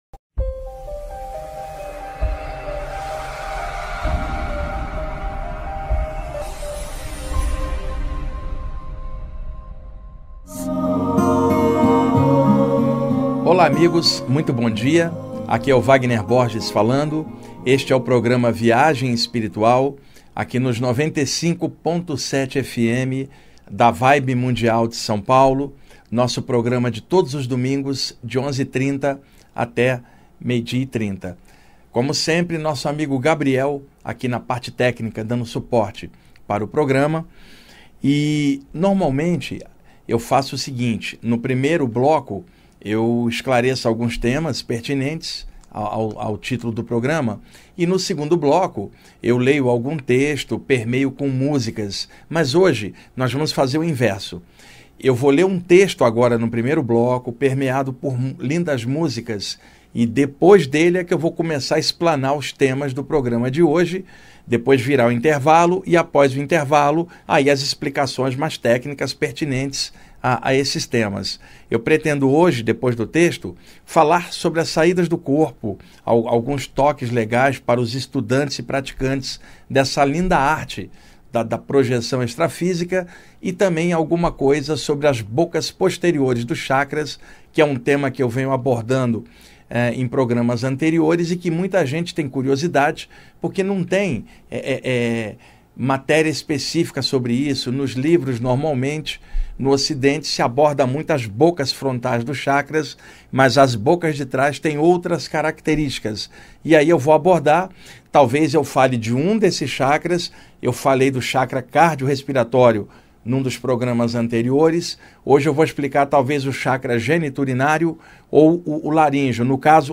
Leitura de Texto, Dica de Prática de Proteção, e Mantras Budistas